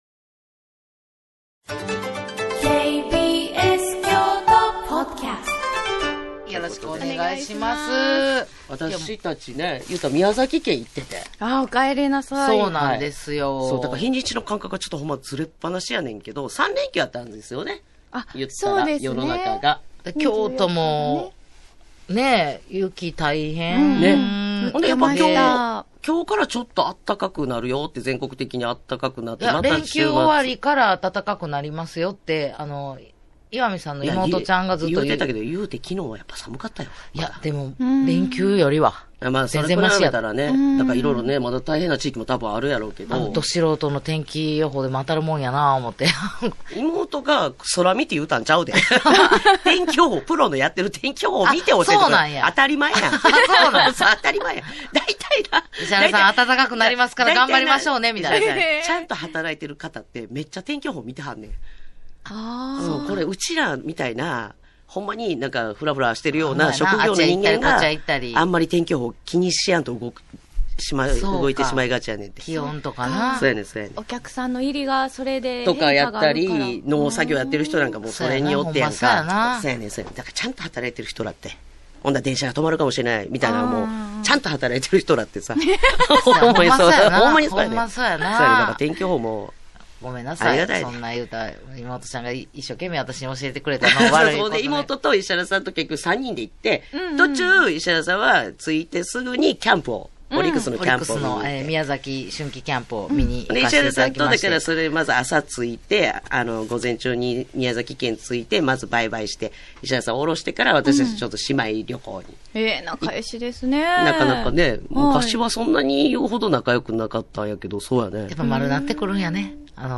【KBS京都ラジオ 毎週水曜日 10:00〜14:00 オンエア】アラサー・アラフォーの独身女子たちが送るバラエティラジオ。グルメや旅行の話題から、今すぐ言いたいちょっとした雑学、みんな大好き噂話まで気になる話題が満載。